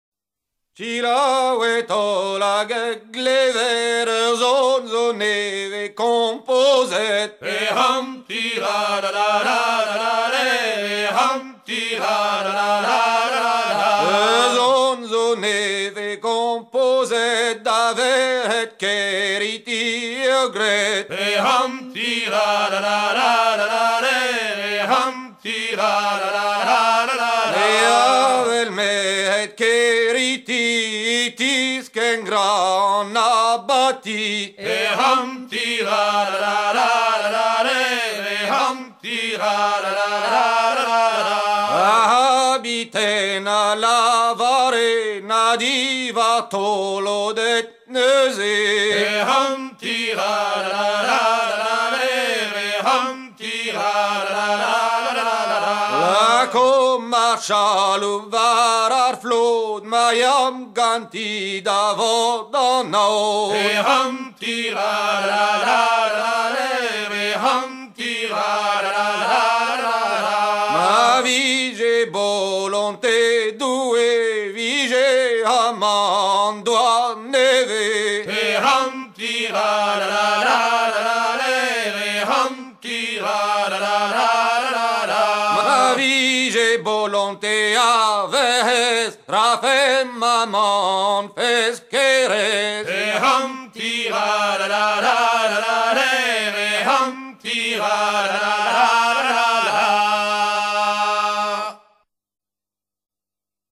gestuel : à hisser main sur main
circonstance : maritimes
Genre laisse